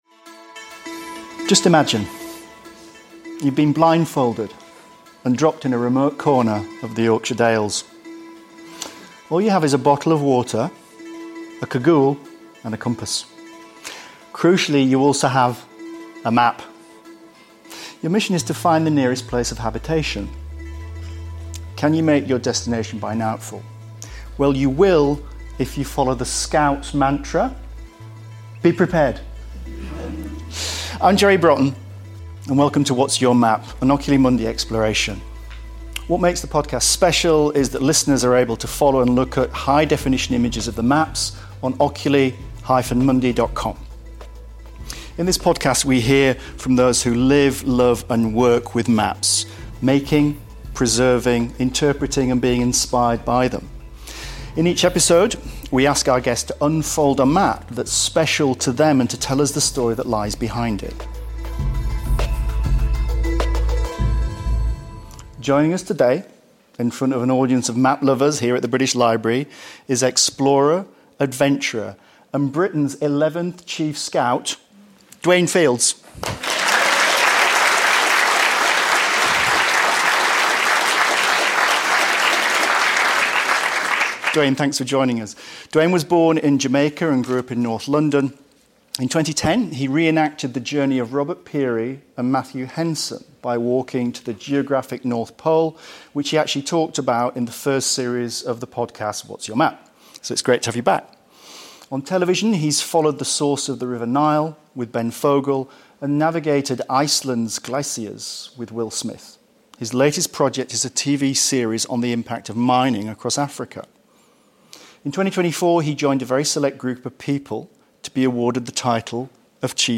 Lessons in Scouting with Dwayne Fields (Live at the British Library)